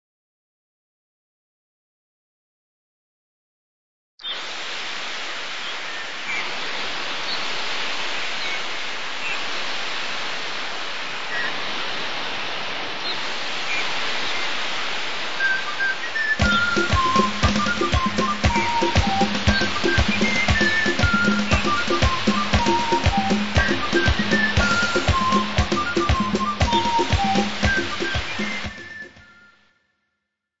INSTRUMENTAL
Pan Flute